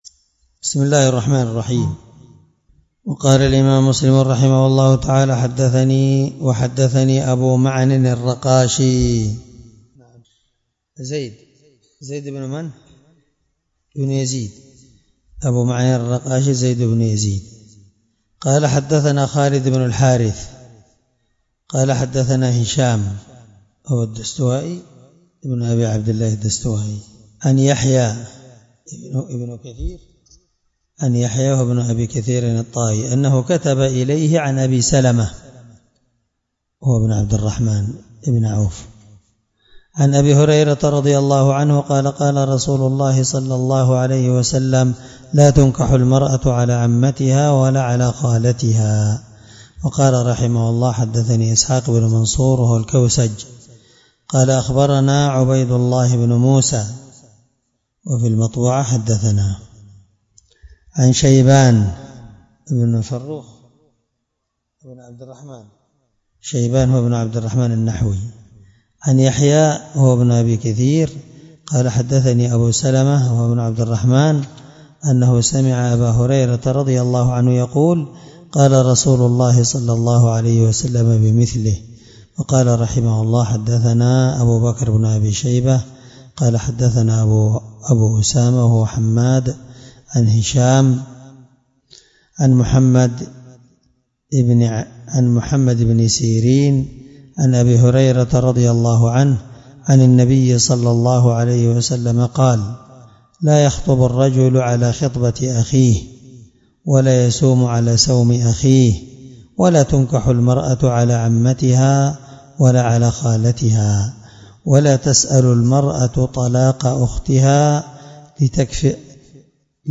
الدرس10من شرح كتاب النكاح حديث رقم(000) من صحيح مسلم